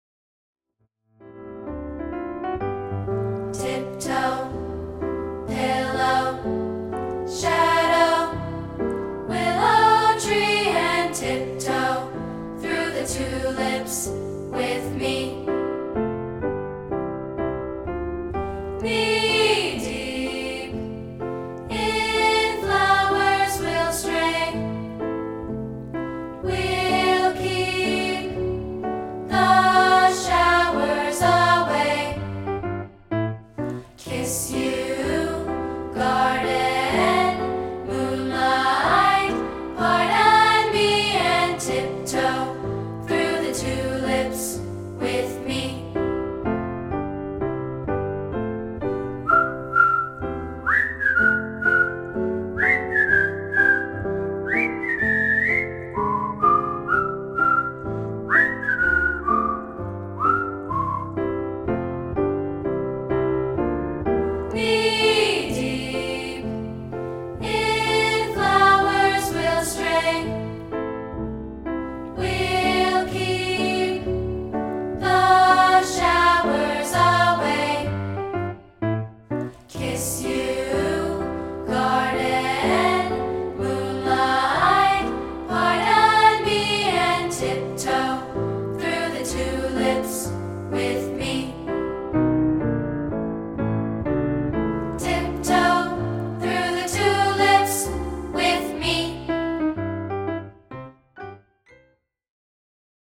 We've created a free rehearsal track of part 2, isolated